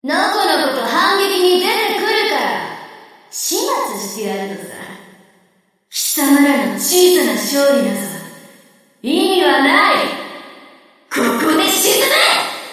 Enemy_Voice_New_Heavy_Cruiser_Princess_Introduction.mp3